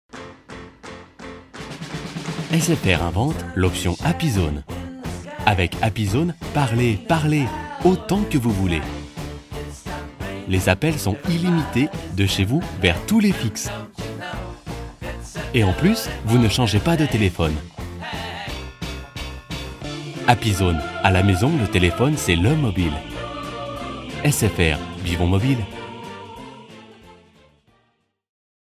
He owns his home studio with ISDN and Neumann microphone His voice and studio are accredited by SaVoa (Society of Accredited Voice Over Artists). His style can be : convincing, reassuring, dynamic, soft, sensitive, elegant, Warm.
Sprechprobe: Werbung (Muttersprache):